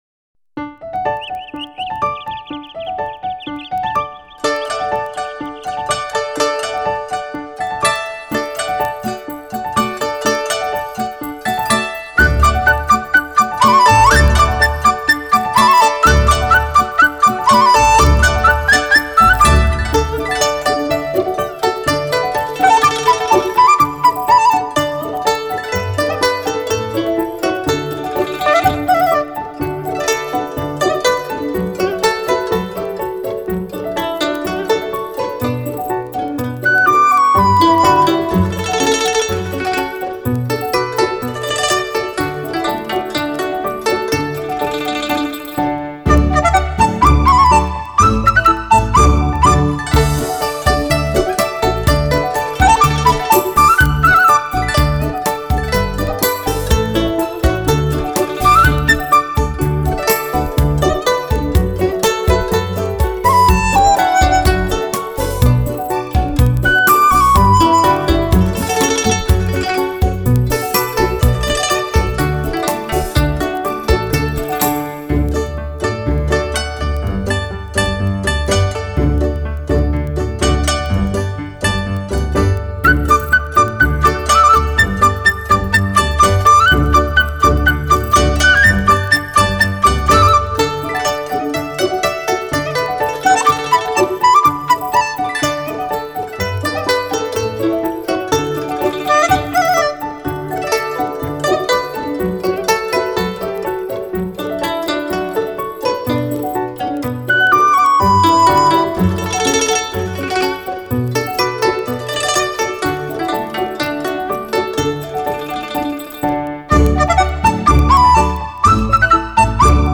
古筝、二胡、竹笛